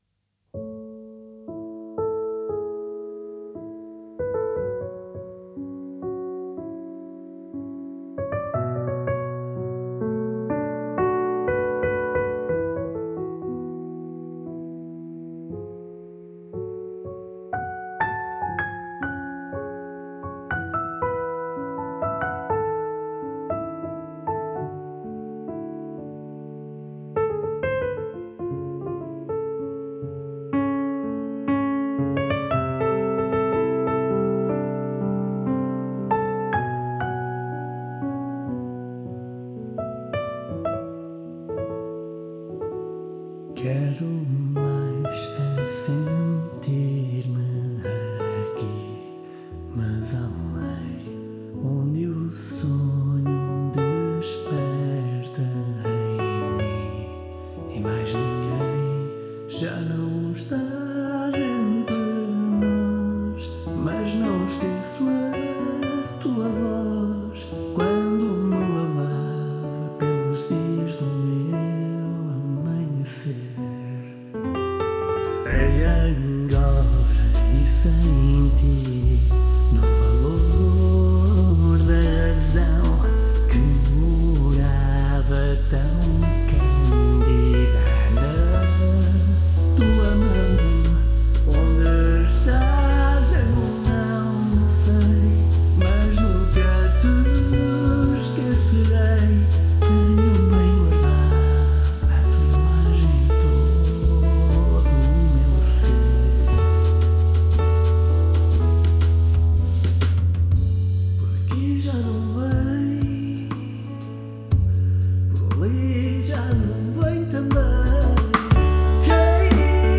vocals/instrumental